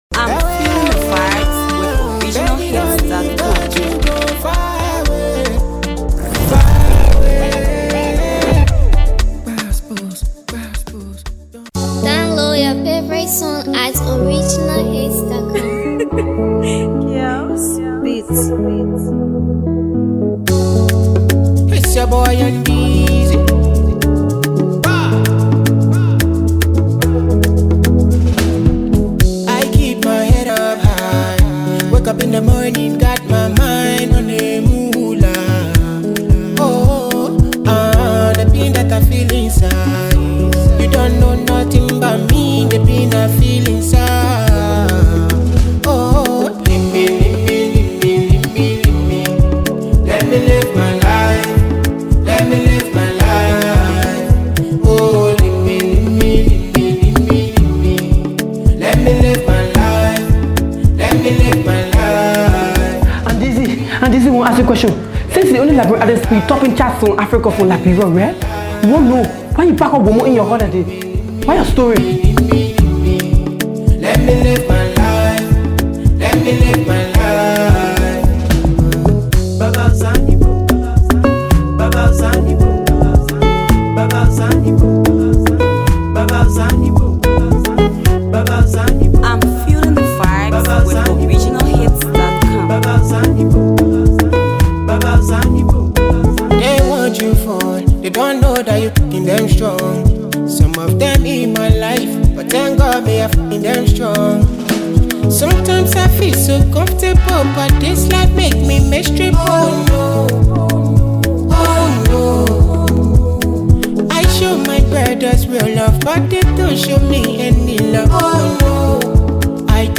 studio sound